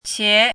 chinese-voice - 汉字语音库
qie2.mp3